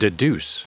Prononciation du mot deduce en anglais (fichier audio)
Prononciation du mot : deduce